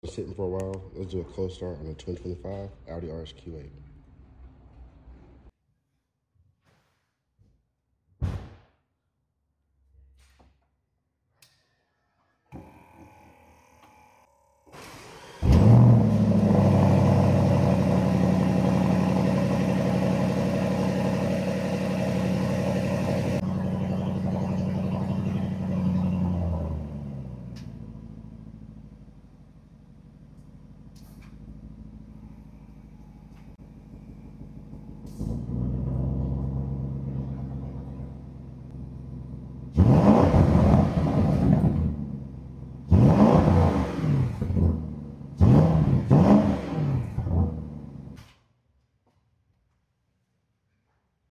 The Most Powerful V8 Audi sound effects free download